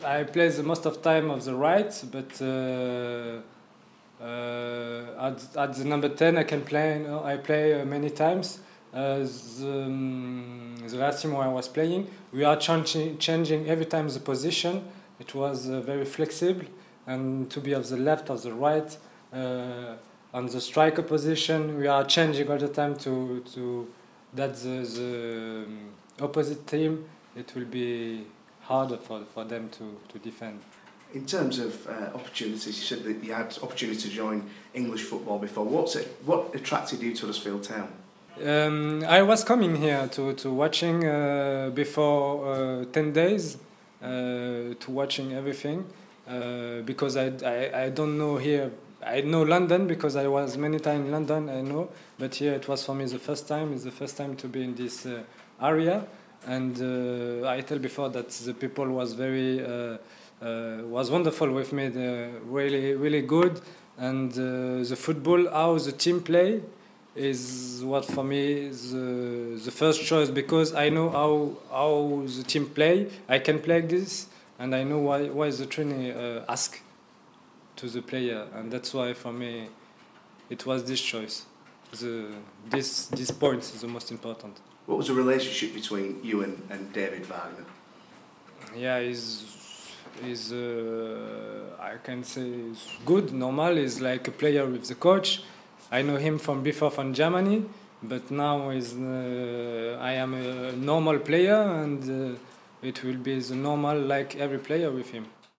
Huddersfield Town's new man Karim Matmour tells Radio Yorkshire why he jumped at the chance to come to West Yorkshire...